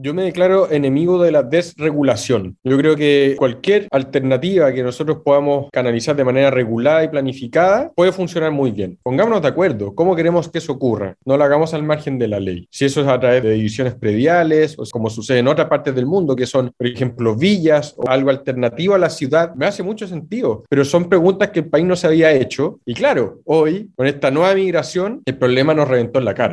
En entrevista con Radio Sago, el alcalde Tomás Garate conversó acerca de la migración interna del país, la que trajo como consecuencia un aumento explosivo de nuevos habitantes en la comuna de Puerto Varas.